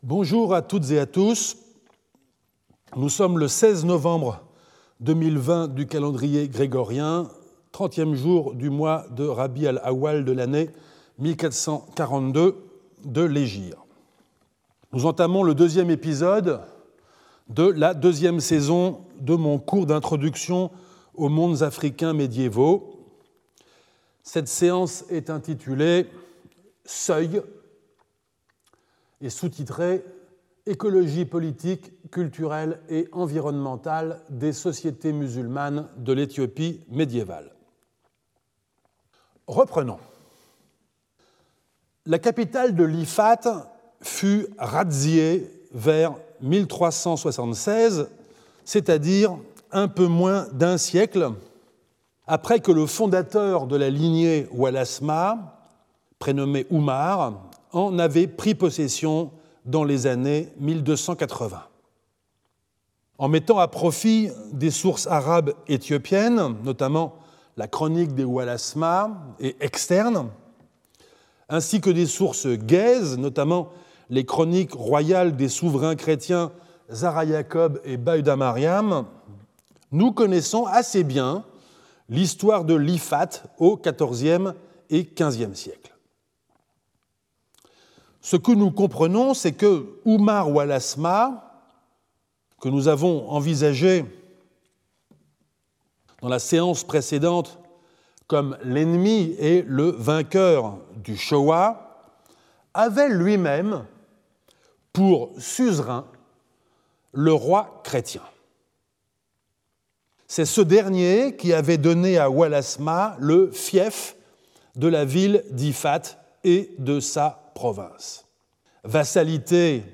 François-Xavier Fauvelle Professor at the Collège de France
Lecture